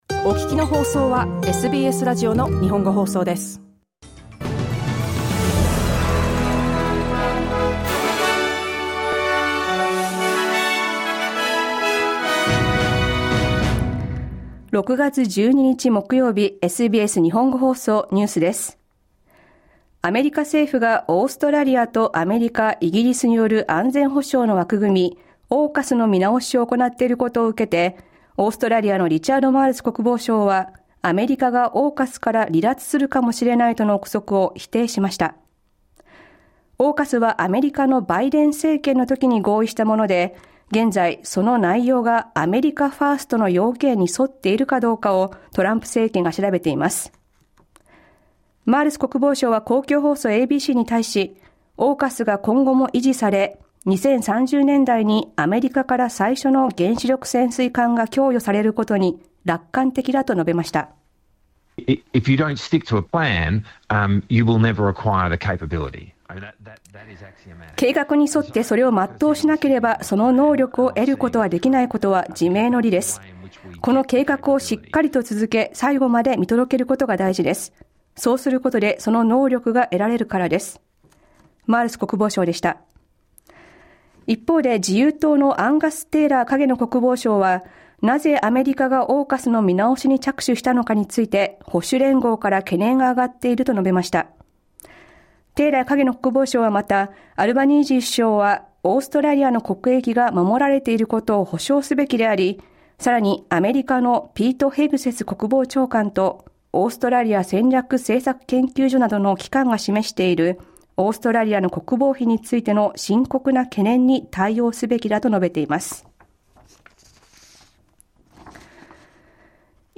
News from today's live program (1-2pm).